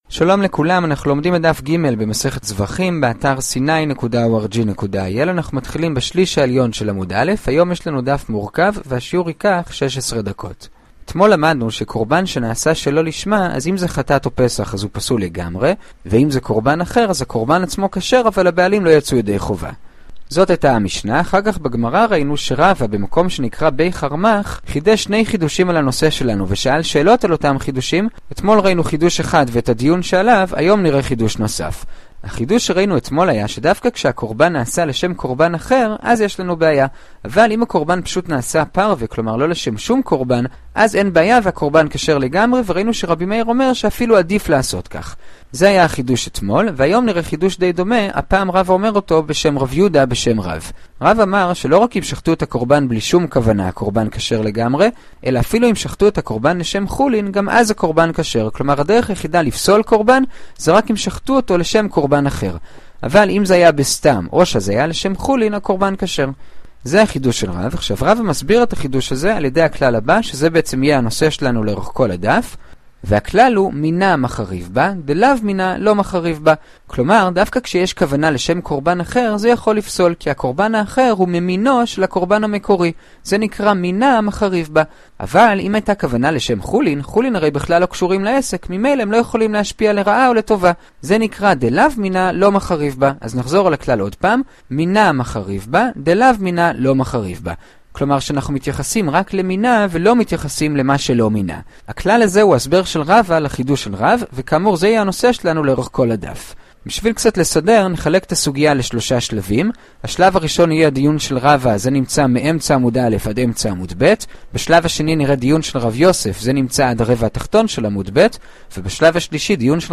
זבחים ג - דף יומי - הדף היומי ב15 דקות - שיעורי דף יומי קצרים בגמרא